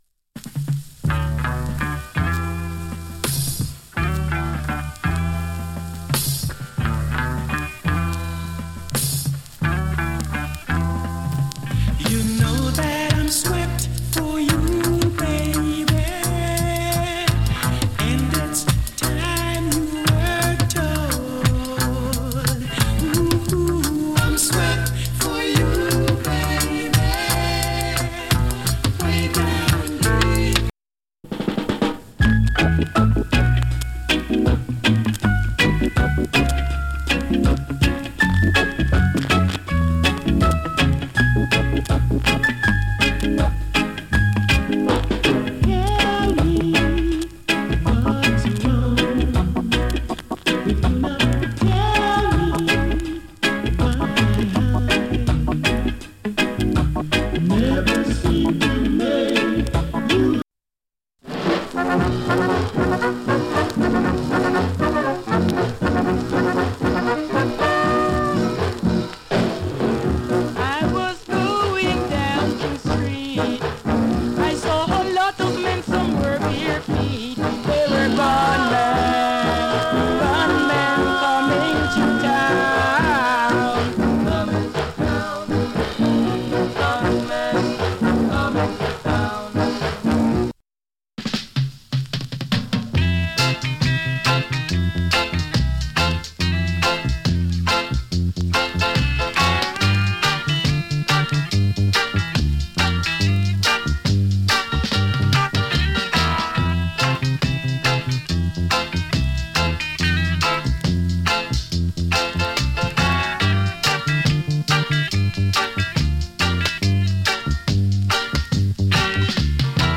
プレスによるノイズ少し有り。